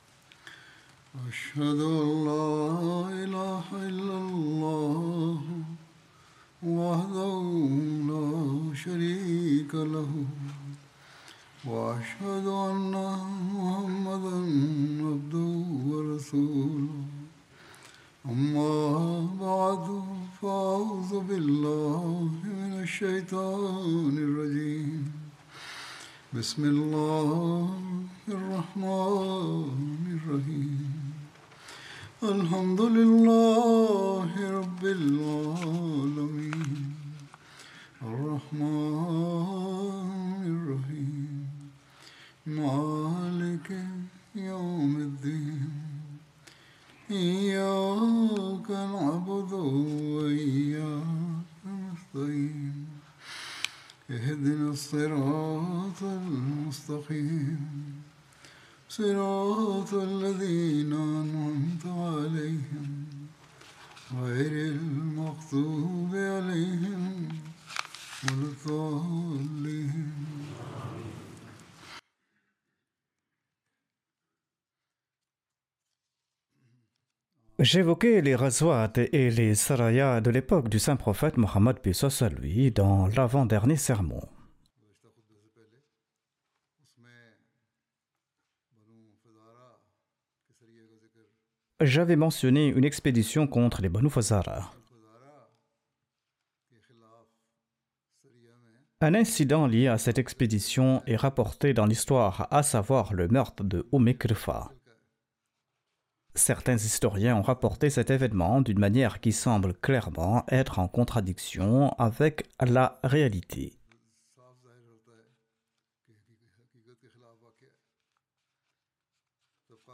French Translation of Friday Sermon delivered by Khalifatul Masih